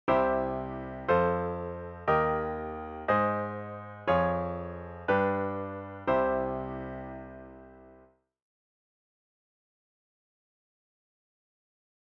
For example, by inverting the Db chord just before the G, placing the F of the Db chord in the bass, that augmented 4th leap is replaced by a whole tone step, which is more easily “understood” by the listener.